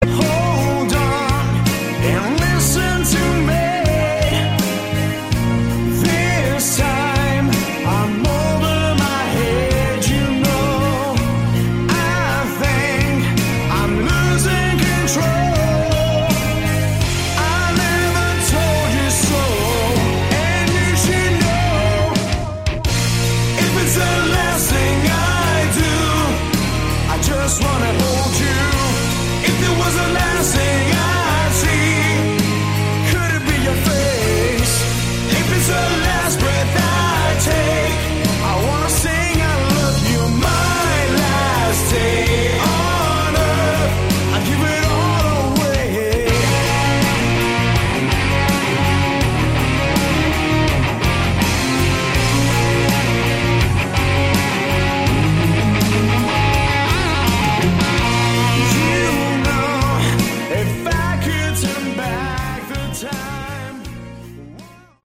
Category: Hard Rock
vocals
guitars
keyboards
bass
drums
Never heard such bad bad voice!!!derrible!!!!